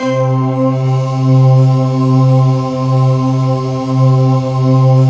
LION-CHOR.wav